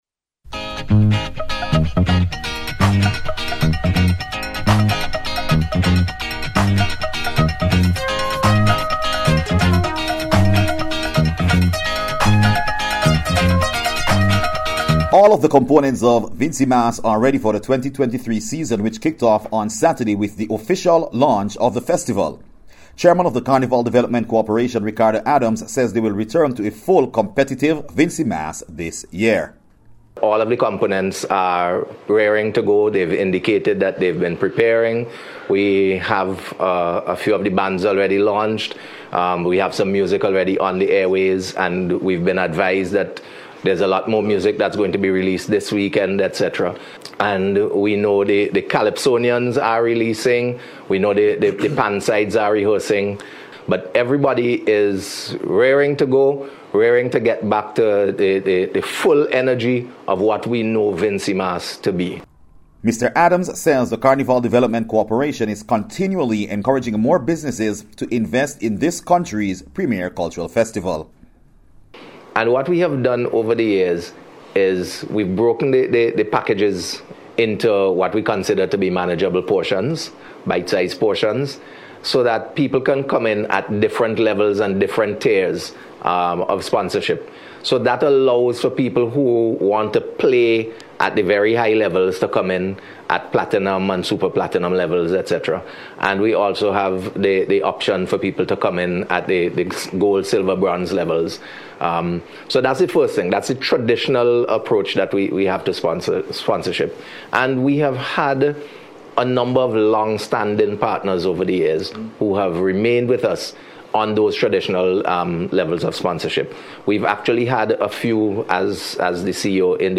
VINCYMAS-KICKS-OFF-REPORT.mp3